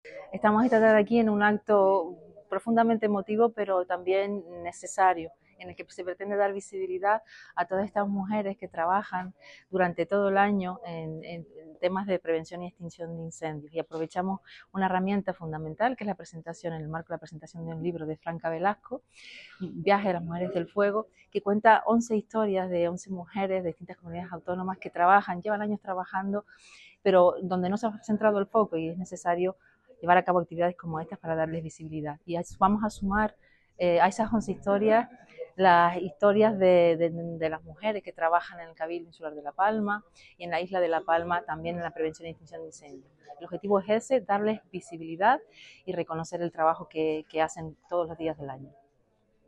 Declaraciones Susa Armas Viaje a las mujeres del fuego_0.mp3